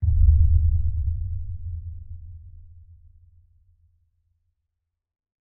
PixelPerfectionCE/assets/minecraft/sounds/mob/guardian/elder_idle4.ogg at mc116
elder_idle4.ogg